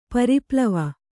♪ pari plava